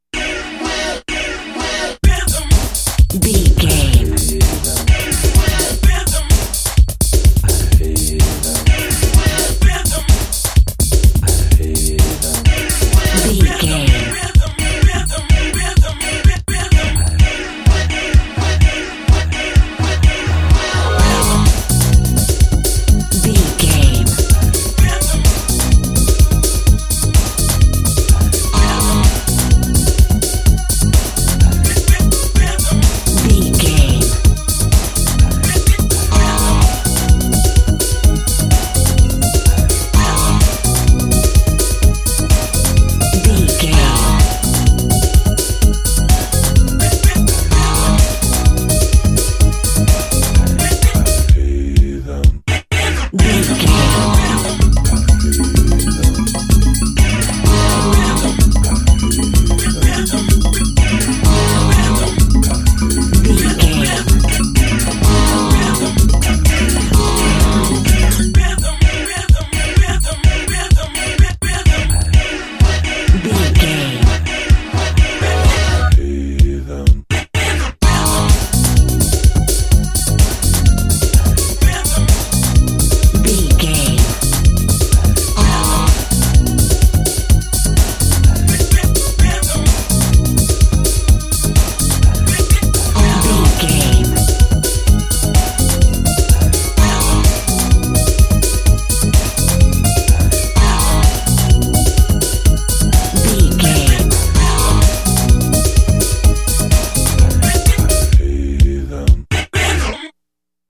Aeolian/Minor
E♭
synthesiser
90s
Eurodance